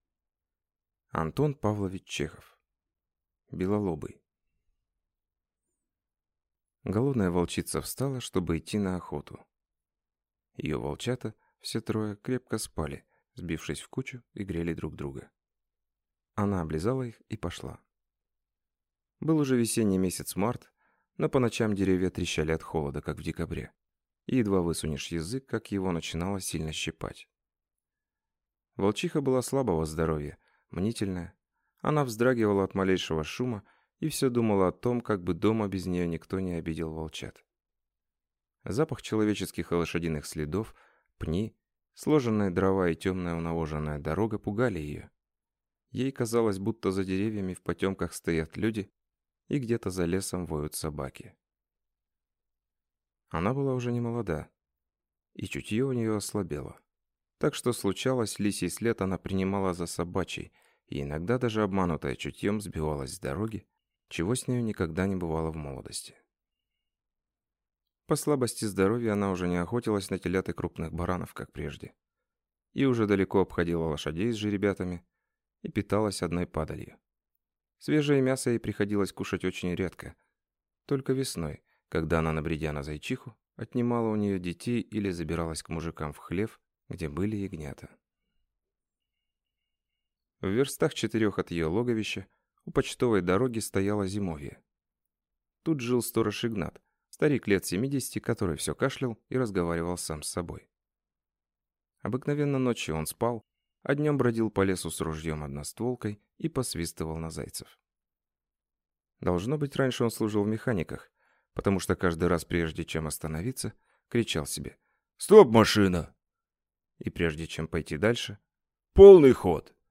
Аудиокнига Белолобый | Библиотека аудиокниг